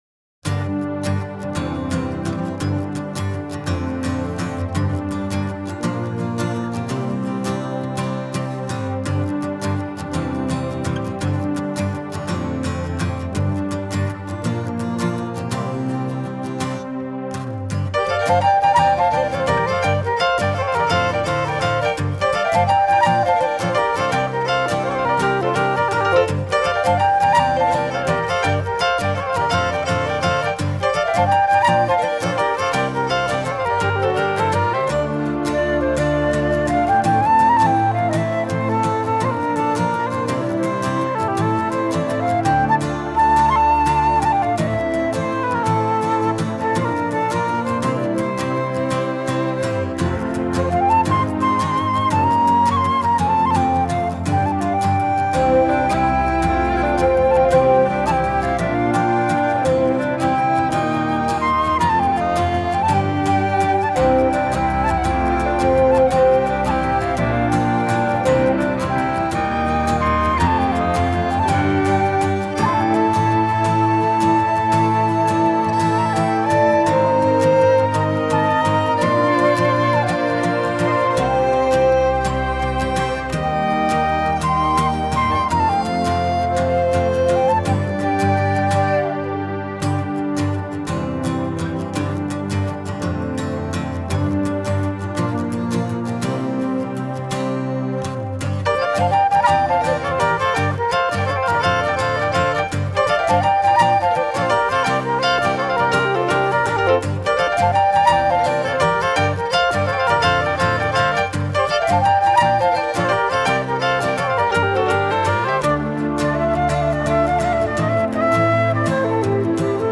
新品/爵士/世界音乐
主奏乐器：人声、民族乐器
克尔特音乐新定义·最后的华丽惊叹号！
其余的曲子是在俄勒冈波特兰的White Horse Studio录音室录制的。